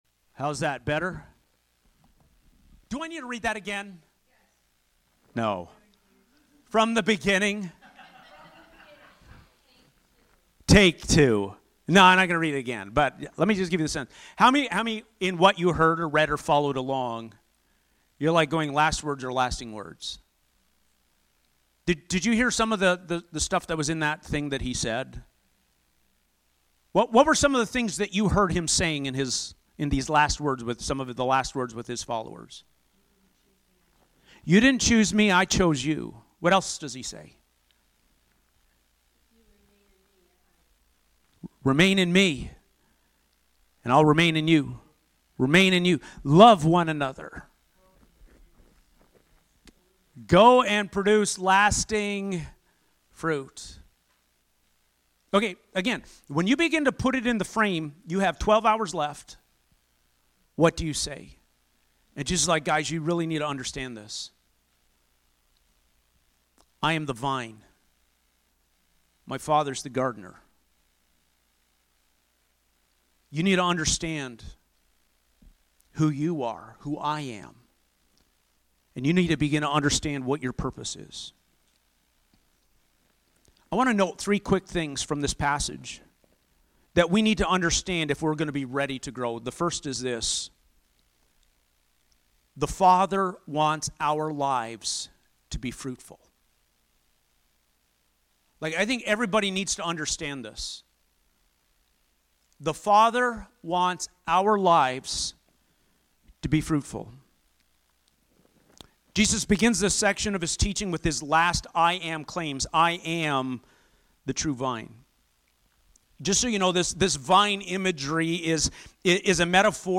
Sermons | Asbury Church